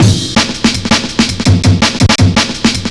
Index of /90_sSampleCDs/Zero-G - Total Drum Bass/Drumloops - 2/track 34 (165bpm)